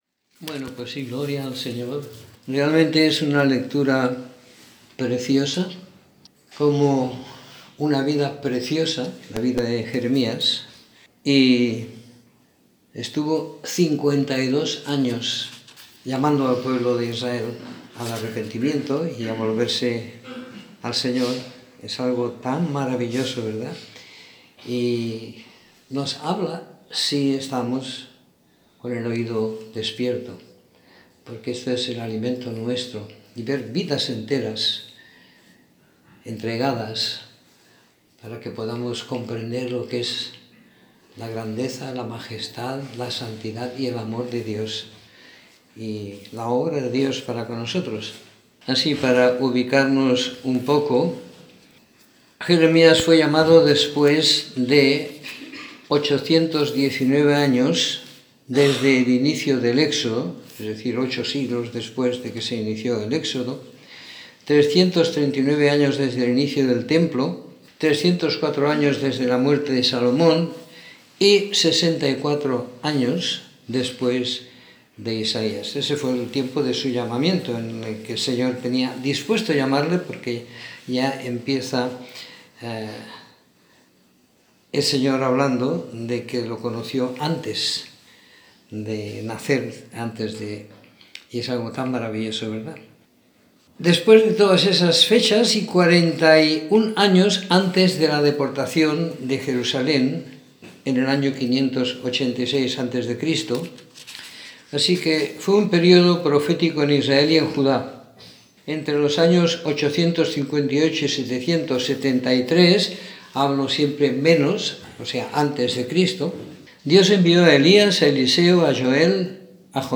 Escuchar la Reunión / Descargar Reunión en audio Comentario en el libro de Jeremías del capítulo 1 al 30 siguiendo la lectura programada para cada semana del año que tenemos en la congregación en Sant Pere de Ribes.